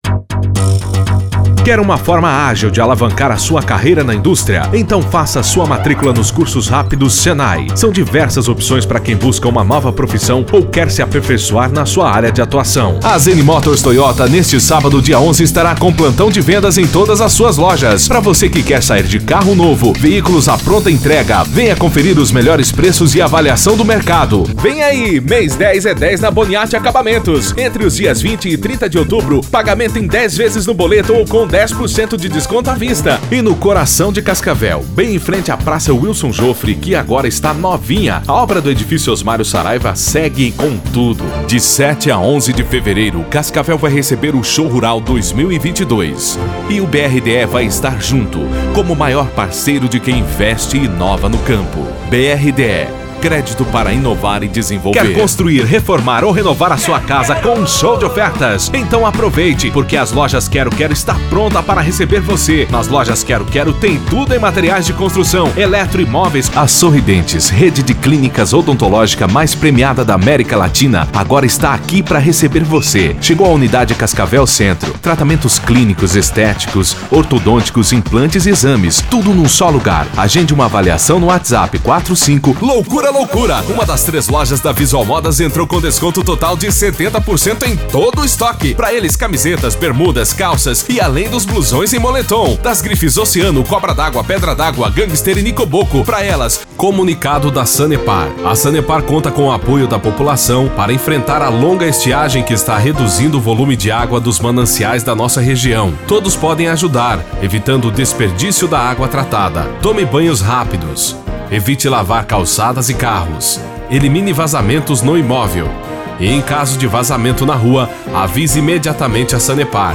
VOZES MASCULINAS
Estilos: Padrão Animada